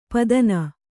♪ padan